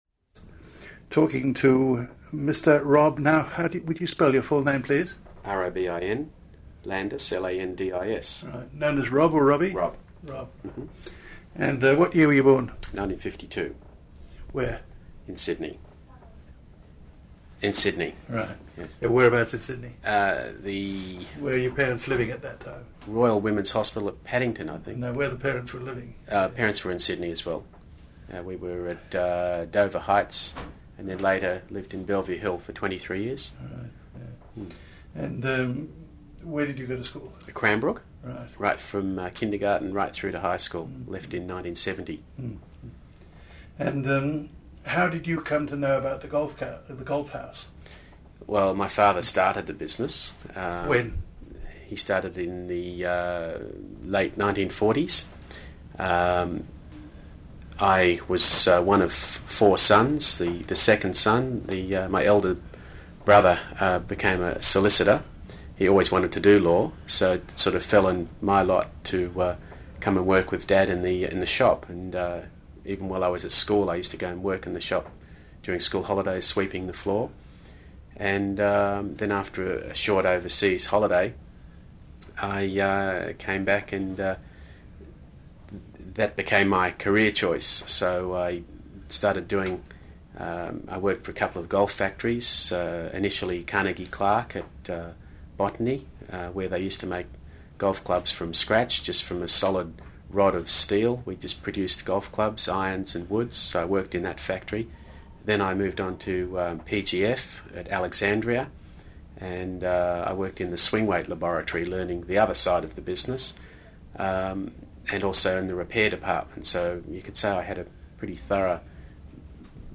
This interview is part of the City of Sydney's oral history theme: Open All Hours